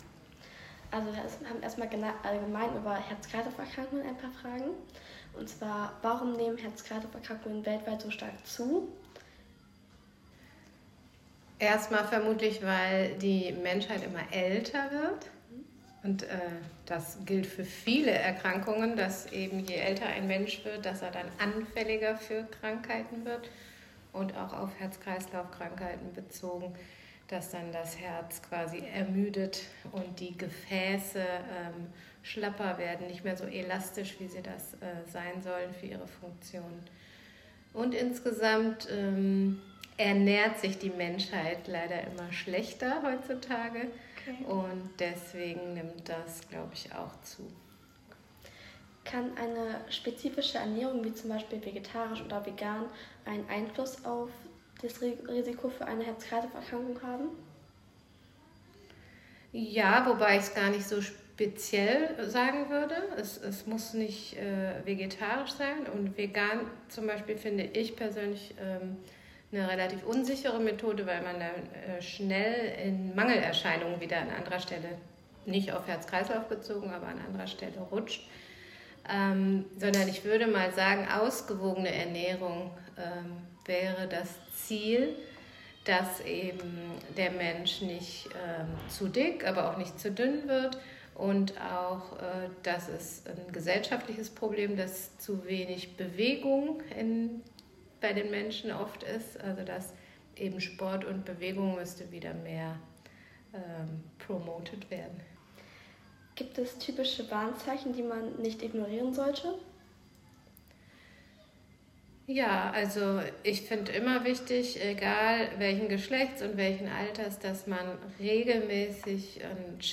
Interview | Heartache
Wir haben mit Einverständnis das Interview aufgezeichnet , und möchten es hier auch in auditiver Form anbieten: Interview Audio